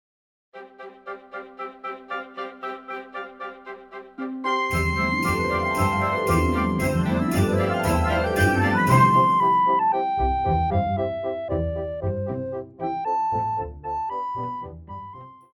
古典
高音直笛
樂團
聖誕歌曲,聖歌,教會音樂,古典音樂
鋼琴曲,演奏曲
獨奏與伴奏
有主奏
有節拍器